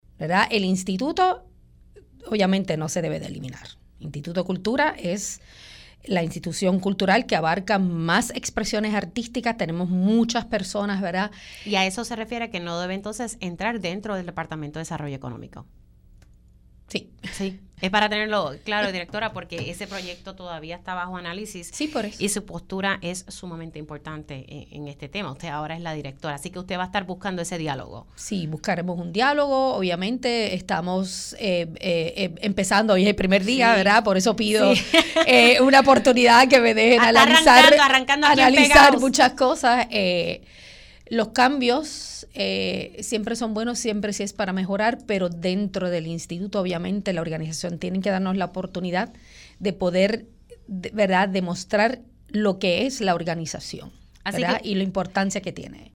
En su primera entrevista como directora ejecutiva, Melissa Santana indicó que su prioridad es analizar el presupuesto asignado de la agencia
La recién designada directora ejecutiva del Instituto de Cultura Puertorriqueña (ICP), Melissa Santana afirmó en Pega’os en la Mañana que no se debe eliminar la institución, a pesar de la medida del presidente del Senado que propone que el ICP caiga bajo el Departamento de Desarrollo Económico y Comercio (DDEC).